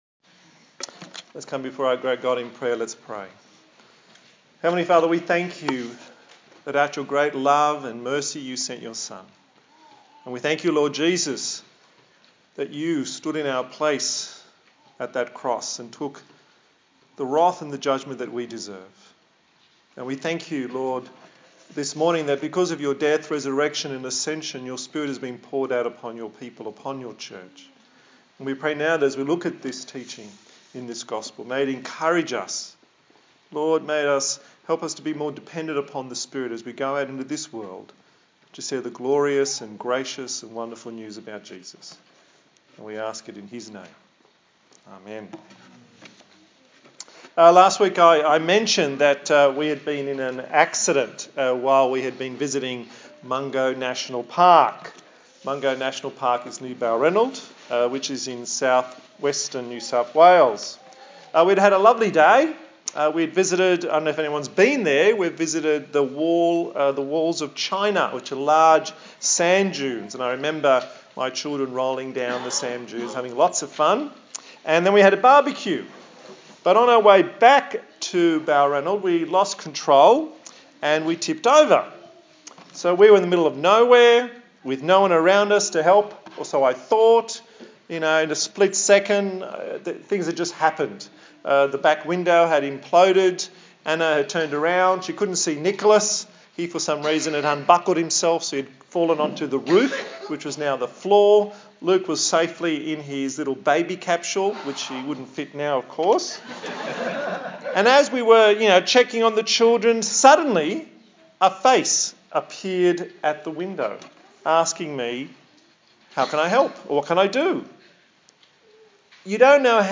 Service Type: Sunday Morning A sermon